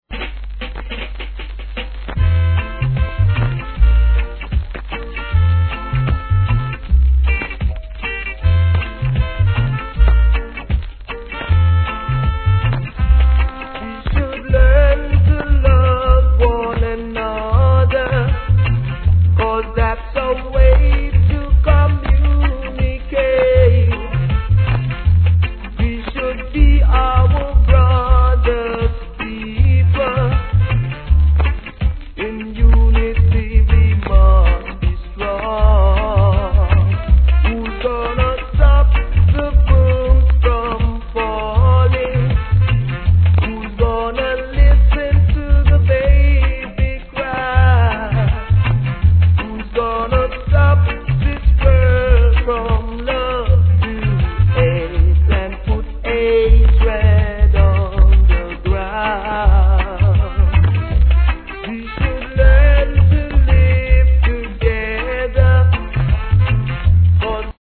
B 〜 C (傷で周期的なノイズ)
REGGAE